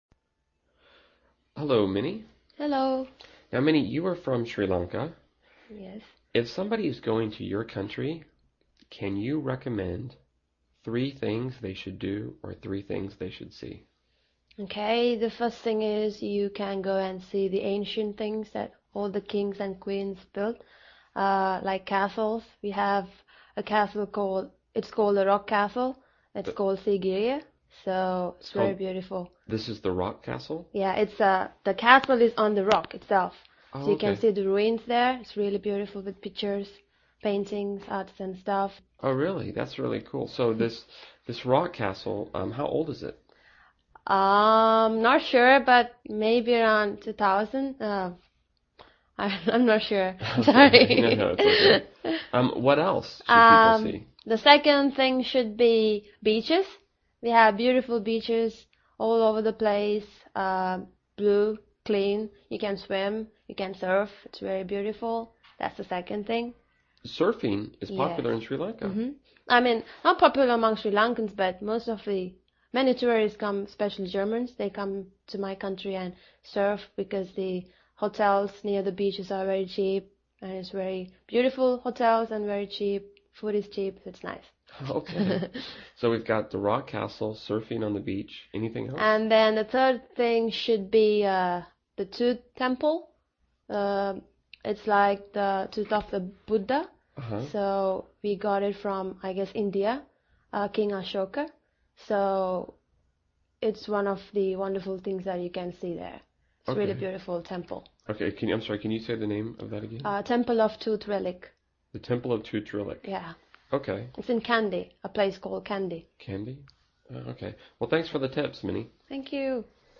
英语初级口语对话正常语速05：斯里兰卡（MP3+lrc）